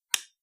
switch4.wav